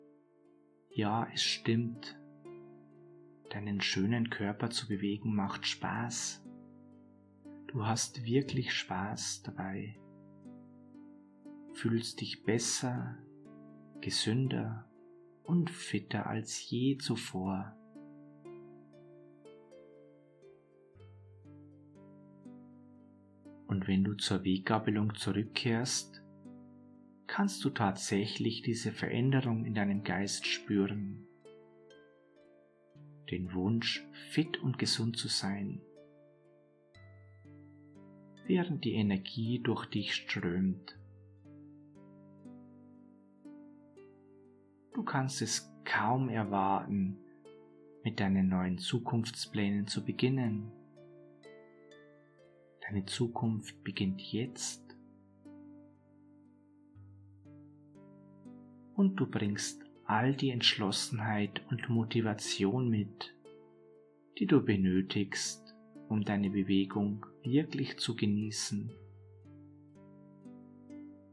1. Geführte Hypnose „C5003 – Mehr Freude an Bewegung“: